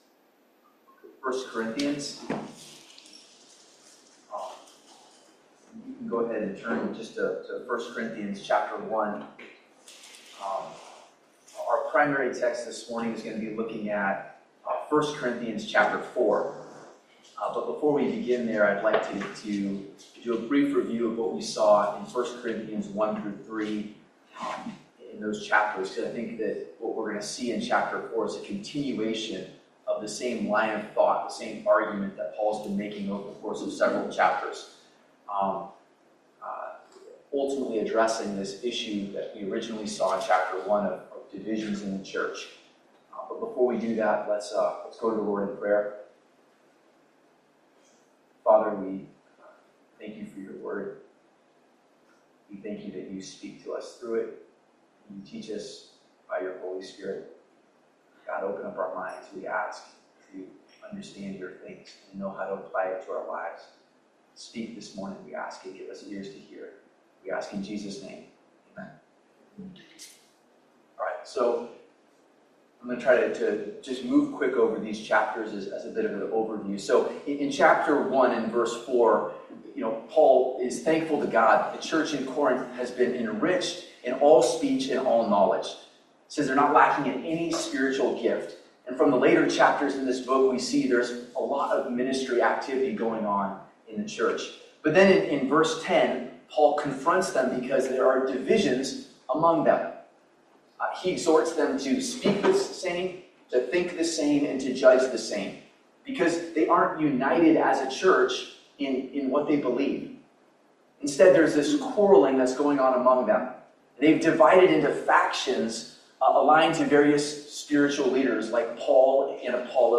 1 Corinthians 4:1-16 Service Type: Family Bible Hour What we choose to do every day matters to God.